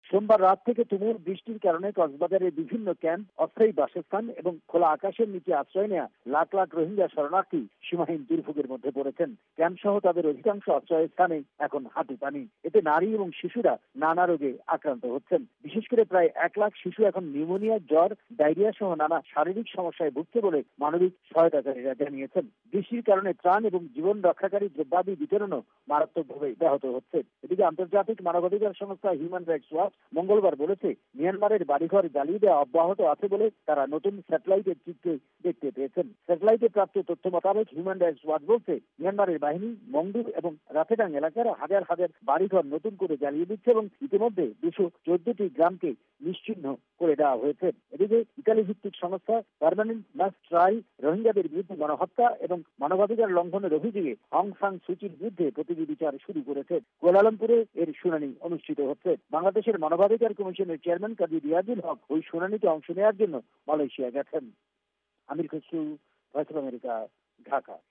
রিপোর্ট রোহিঙ্গা